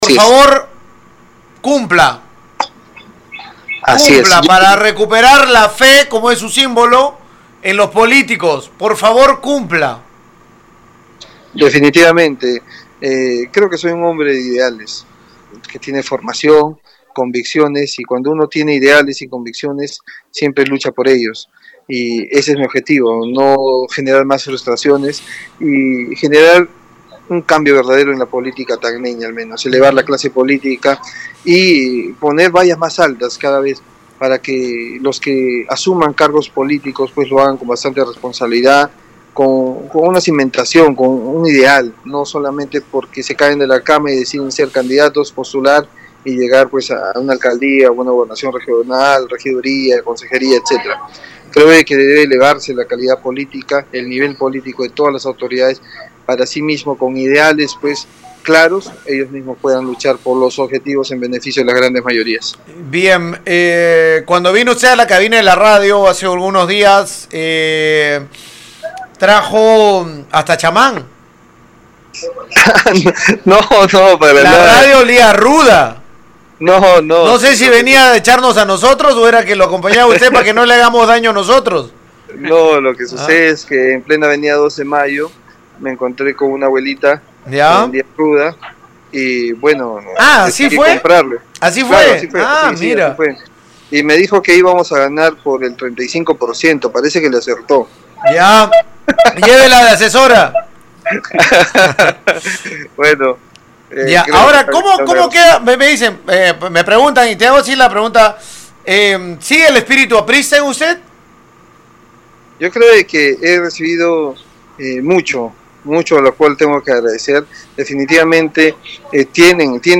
En primeras declaraciones como posible autoridad, Zavala de 36 años de edad expresó a Radio Uno que el 100% de mesas están cubiertas por personeros a fin de defender los votos hasta finalizar el conteo. En cuanto a un futuro gobierno dijo que apunta a elevar la clase política y colocar vallas más altas para próximos burgomaestres.